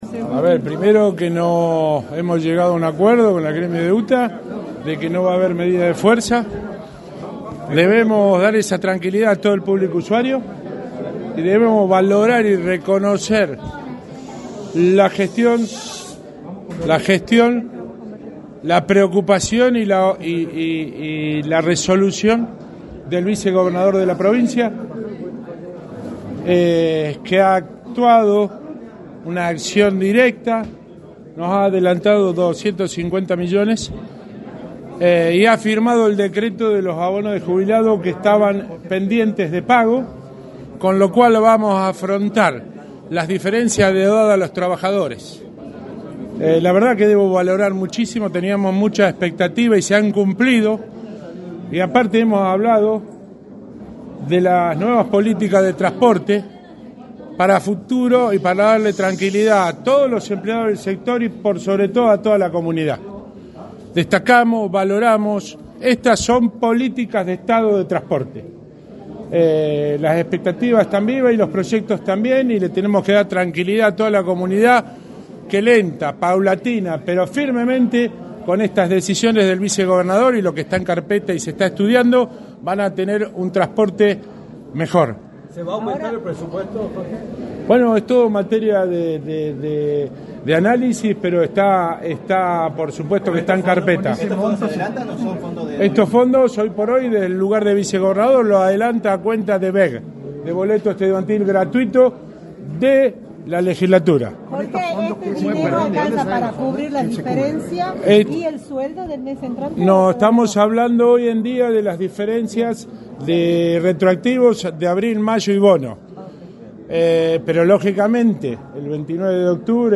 confirmó en Radio del Plata Tucumán